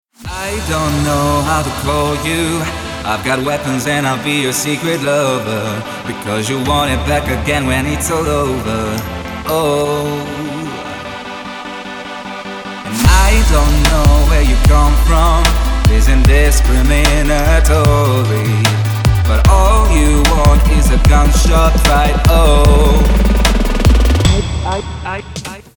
• Качество: 320, Stereo
поп
мужской вокал
club
vocal